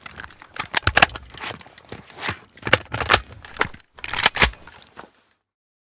akm_reload_empty.ogg